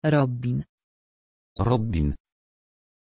Aussprache von Robbin
pronunciation-robbin-pl.mp3